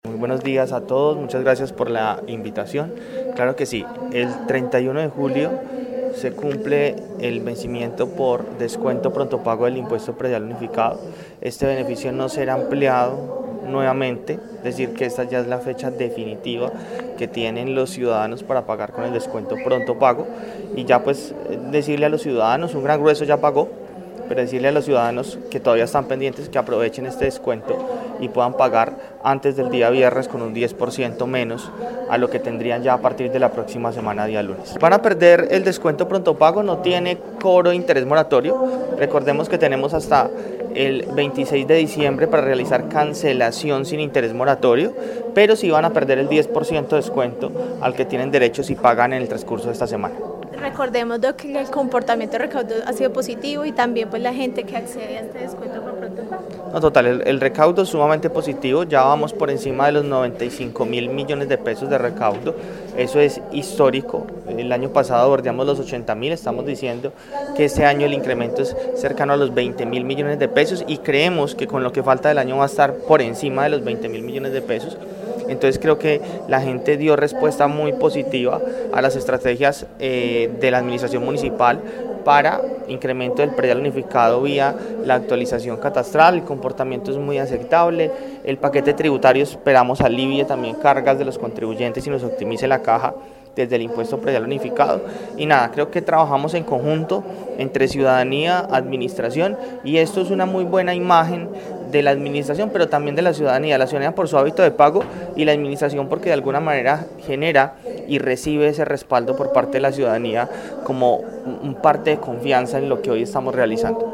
Secretario de Hacienda de Armenia